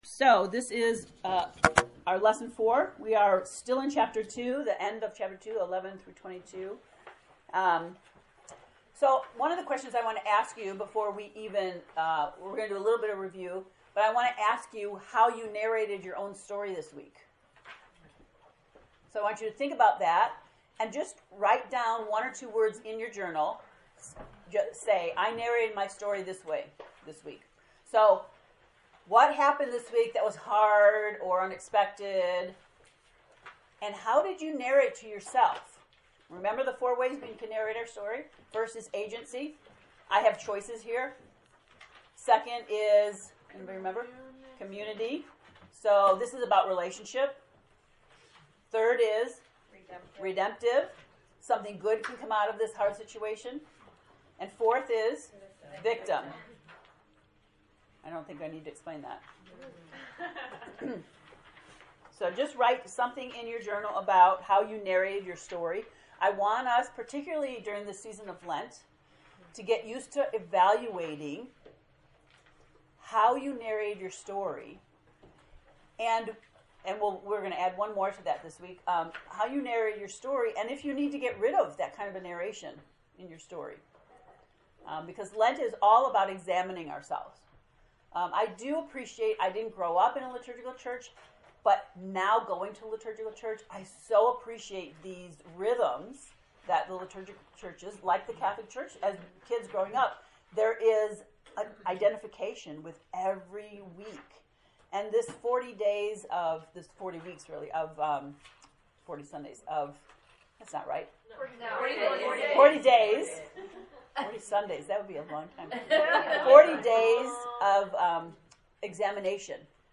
To listen to the lecture from lesson 4, “A New Body,” click below: